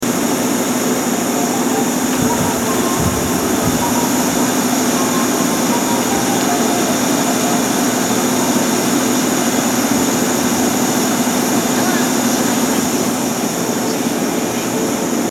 西国立駅　Nishi-Kunitachi Station ◆スピーカー：Roland
2番線発車メロディー